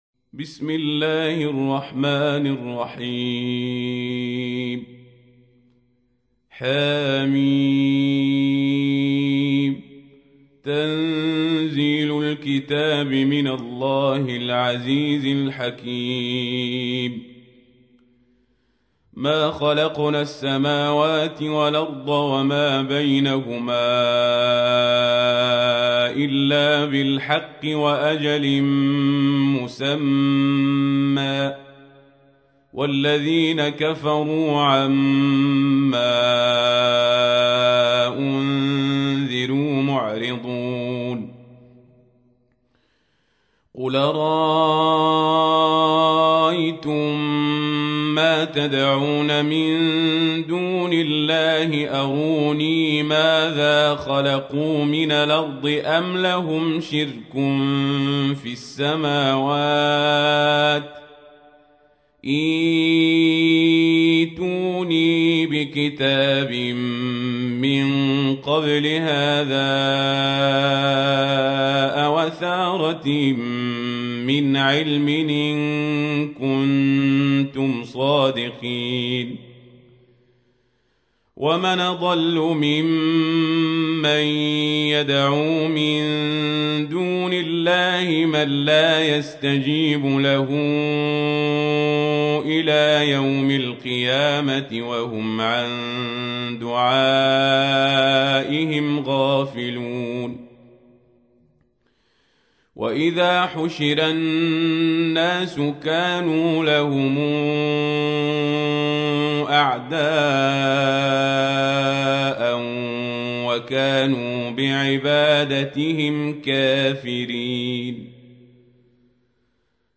تحميل : 46. سورة الأحقاف / القارئ القزابري / القرآن الكريم / موقع يا حسين